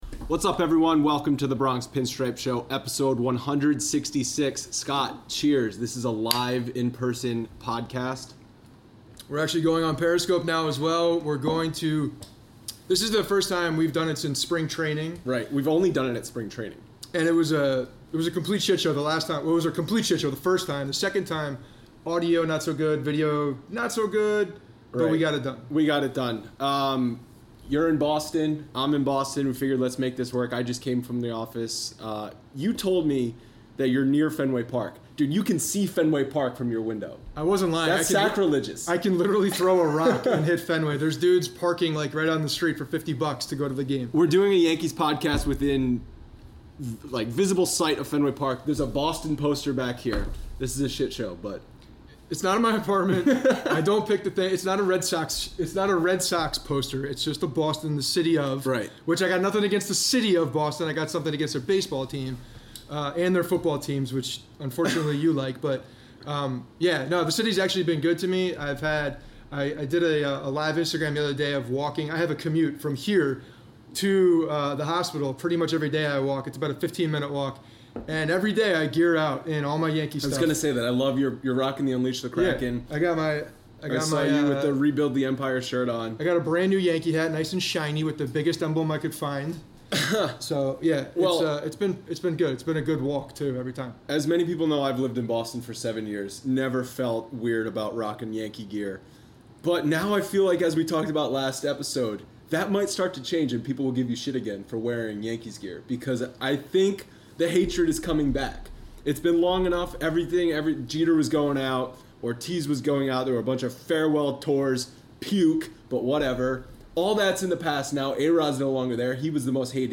The Bronx Pinstripes Show #166: Live and in-person recording of the podcast! Topics include rivalry week, how the Yankees rebounded after the tough Sunday night loss, Yankees offense struggling against mediocre pitchers, Aaron Hicks’ return from the DL, Gary Sanchez responding to being benched, and Masahiro Tanaka’s surprising numbers since early June.